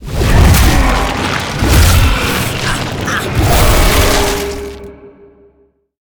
File:Sfx creature shadowleviathan soloattack 01.ogg - Subnautica Wiki
Sfx_creature_shadowleviathan_soloattack_01.ogg